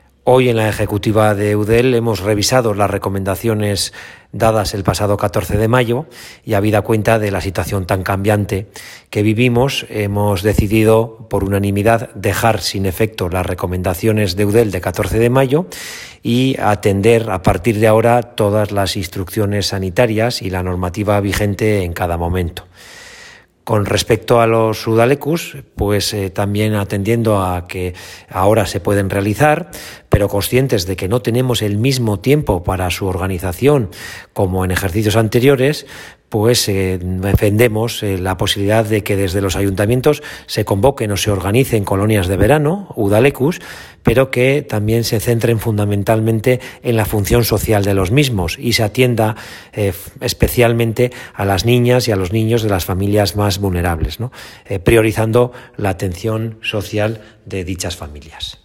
AUDIOS GORKA URTARAN: